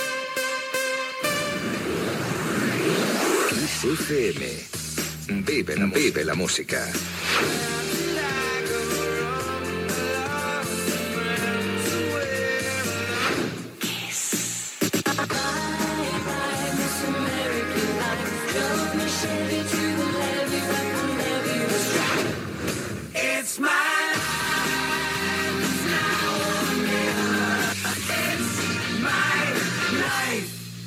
Indicatiu de l 'emissora